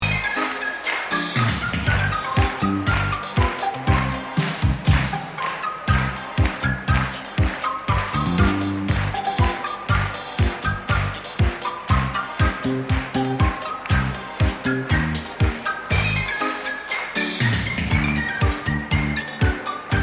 Rosickeho Stadion Spartakiada - Starsie ziacky 1990.mp3